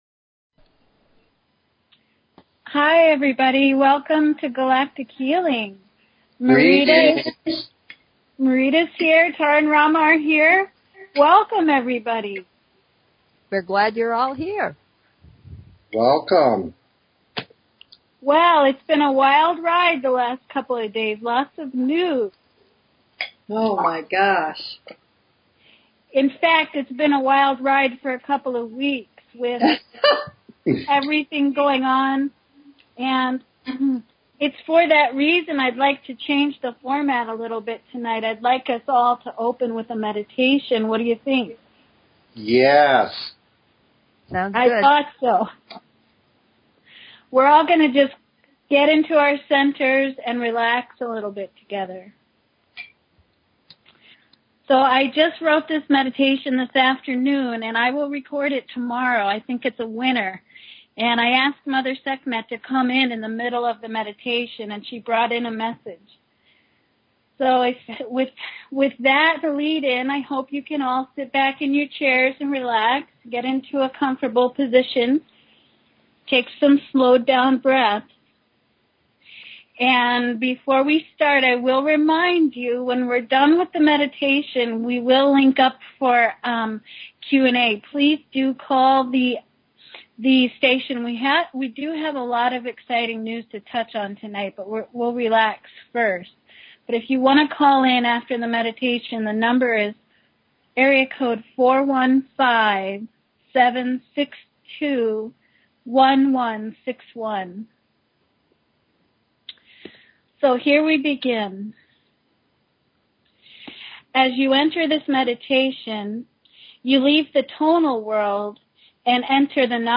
Talk Show Episode, Audio Podcast, Galactic_Healing and Courtesy of BBS Radio on , show guests , about , categorized as